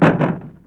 Index of /90_sSampleCDs/E-MU Producer Series Vol. 3 – Hollywood Sound Effects/Water/Falling Branches
FALLING B09R.wav